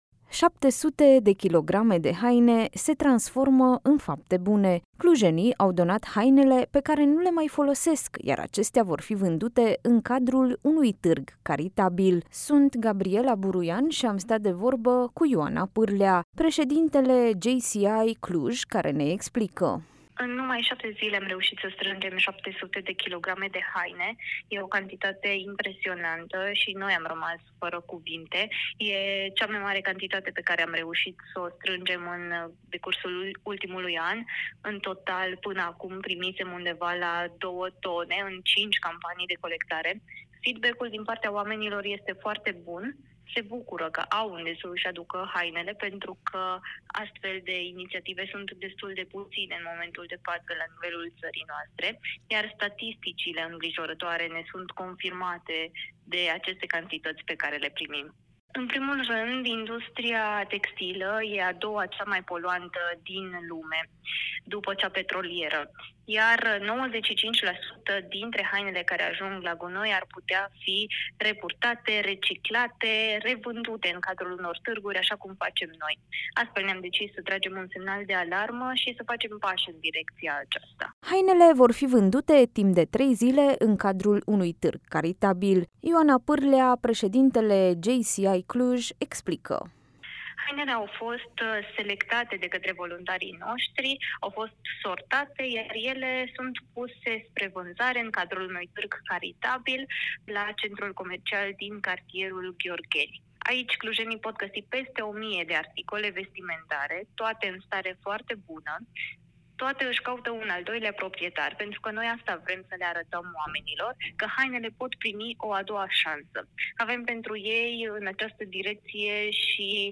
astat de vorbă cu